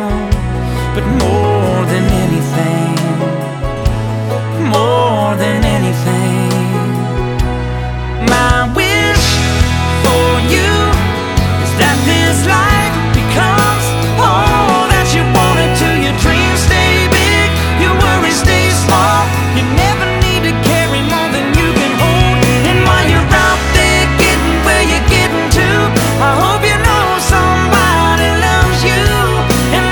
• Pop
American country music group